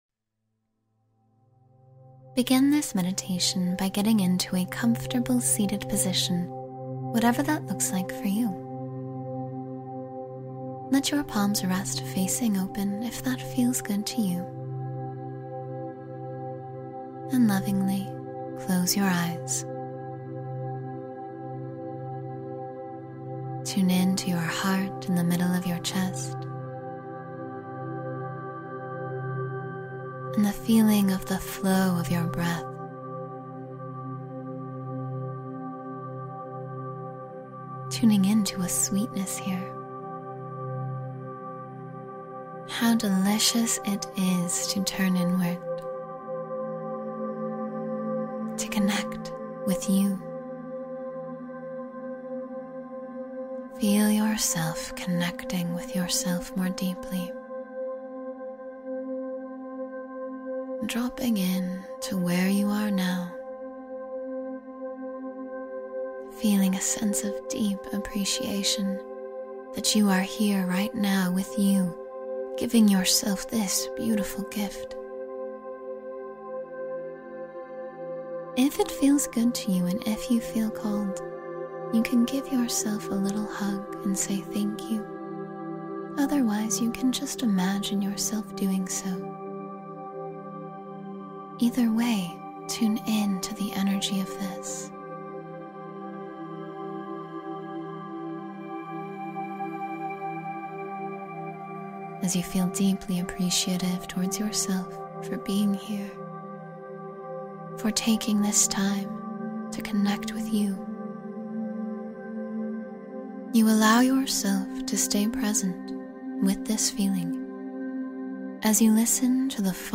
Positive Thinking Affirmations — 10-Minute Meditation for Optimism